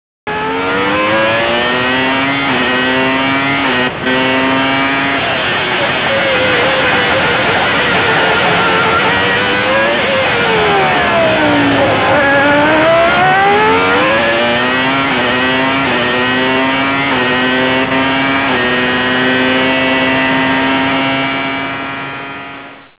Hör hur "körbar" en modern V10 motor är jämfört med en äldre V12 motor.